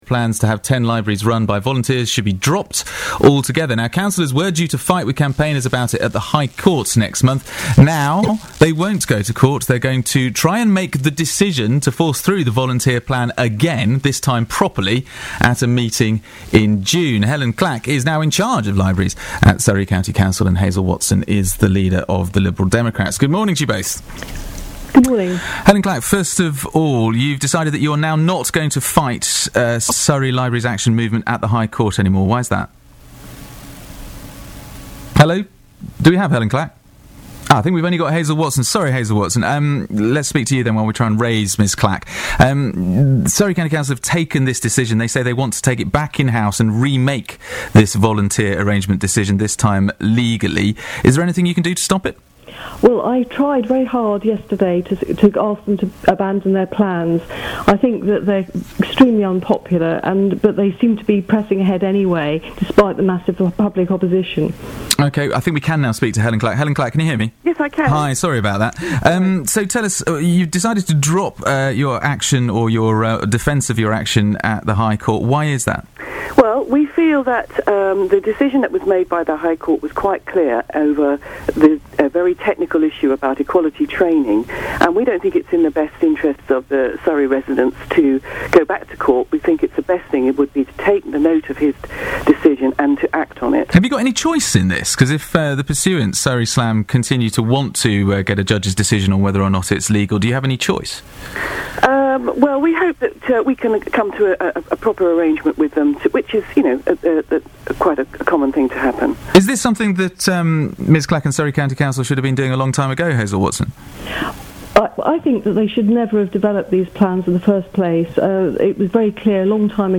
BBC Surrey interview on libraries plans
It features Surrey County Council’s Cabinet Member for Community Services and the 2012 Games Helyn Clack.